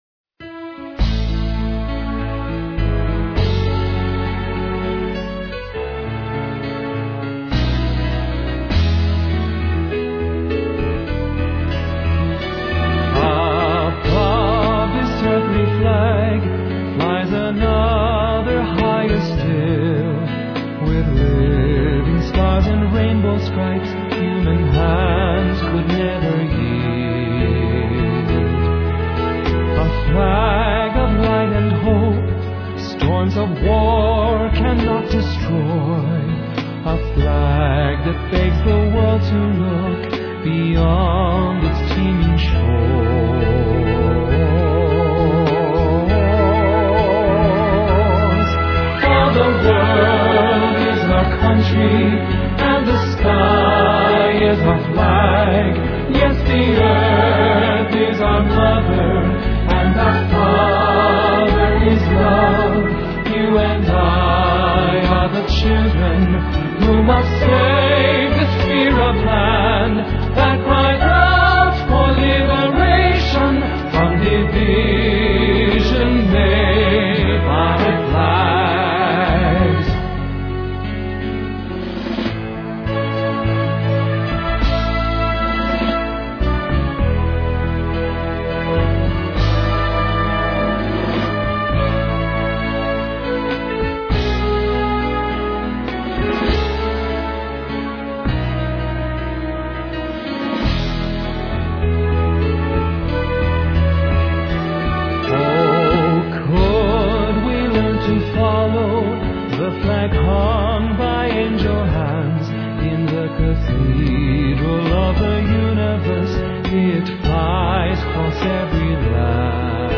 A new flag anthem